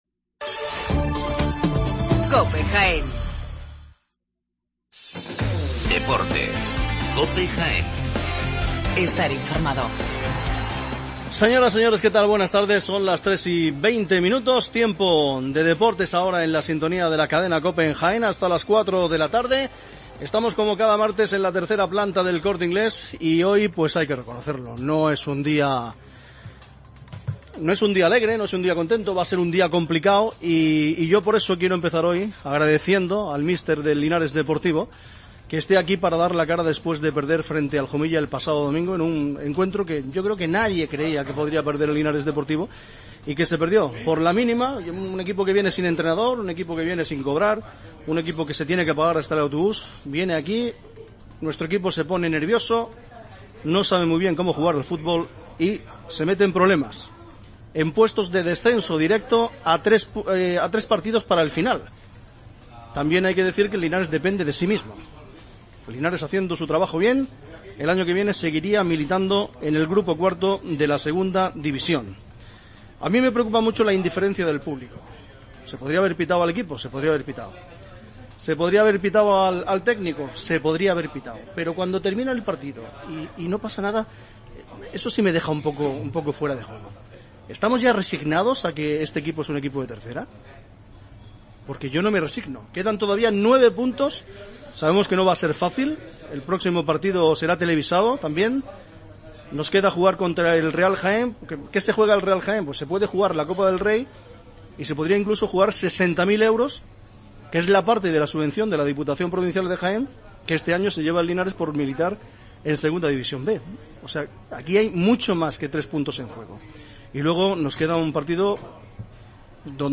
Tertulia deportiva desde Linares 26 de abril de 2016